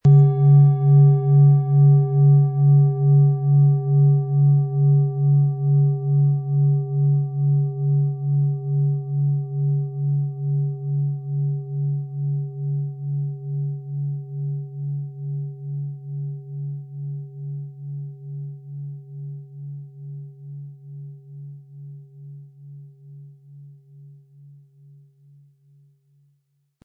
Handgearbeitete tibetische Schale mit dem Planetenton Mars.
• Tiefster Ton: Mond
PlanetentöneMars & Mond & OM-Ton (Höchster Ton)
MaterialBronze